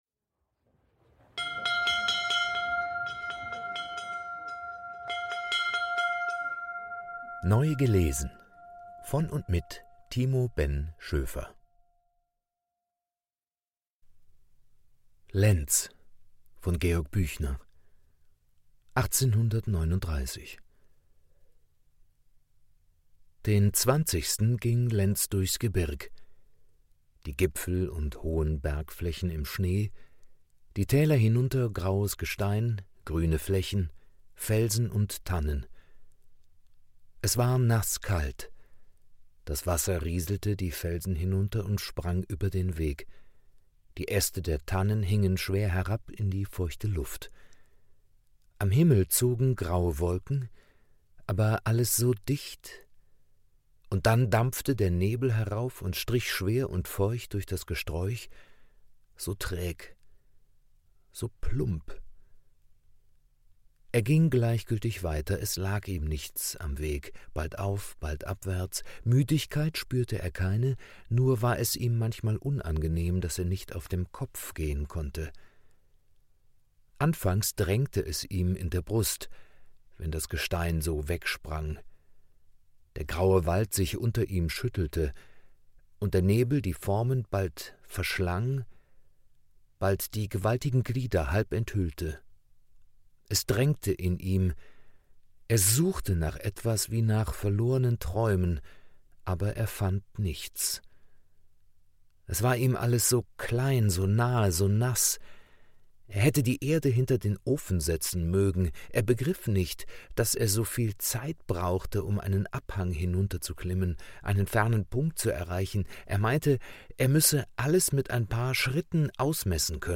Literatur des 19.Jahrhunderts,vorgelesen